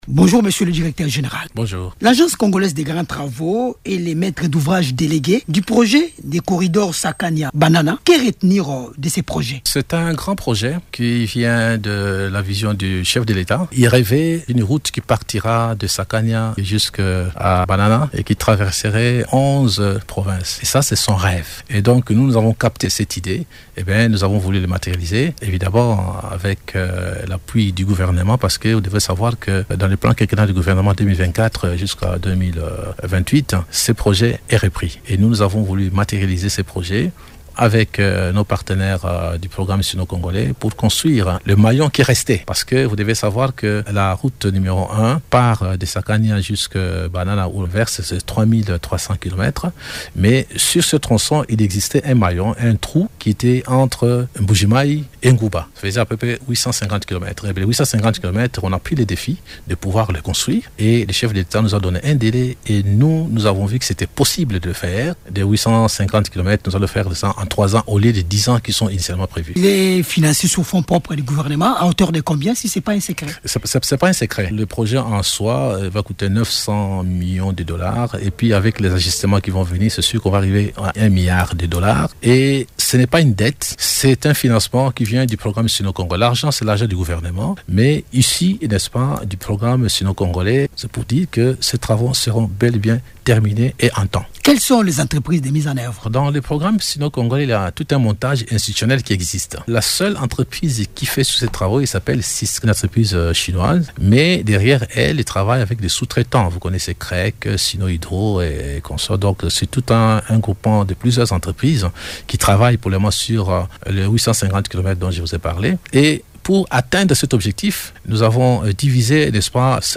Invité de Radio Okapi ce mardi 4 novembre, le Directeur général de l’ACGT, Nico Nzau Nzau, a indiqué que l’ensemble des travaux devrait être finalisé d’ici fin 2027, conformément au calendrier du gouvernement.